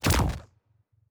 pgs/Assets/Audio/Sci-Fi Sounds/Weapons/Weapon 10 Shoot 5.wav at 7452e70b8c5ad2f7daae623e1a952eb18c9caab4
Weapon 10 Shoot 5.wav